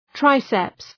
Προφορά
{‘traıseps}